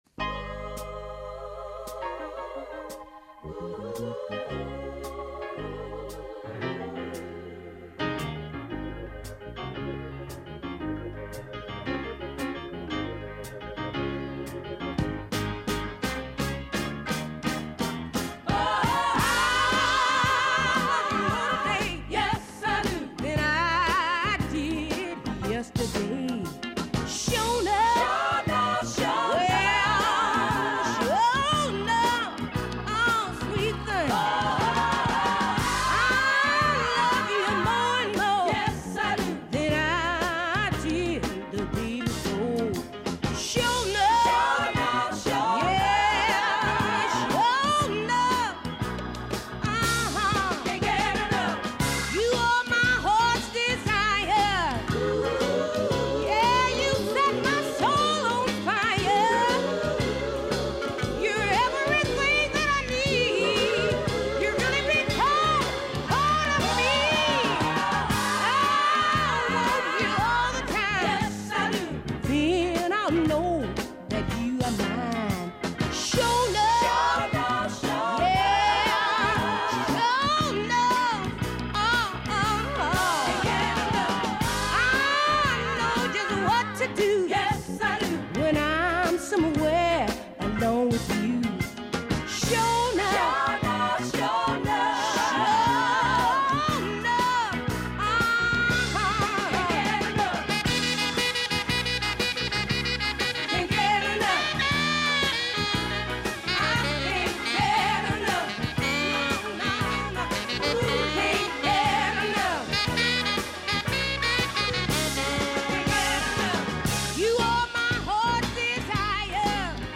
Intervista a Nicola Gardini